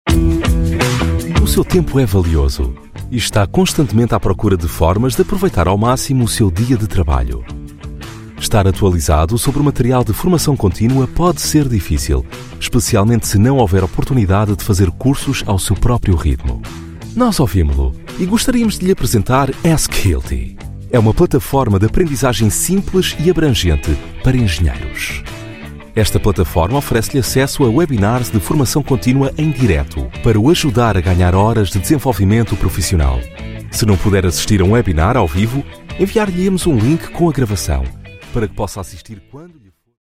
Voice Samples: Corporate Reads
male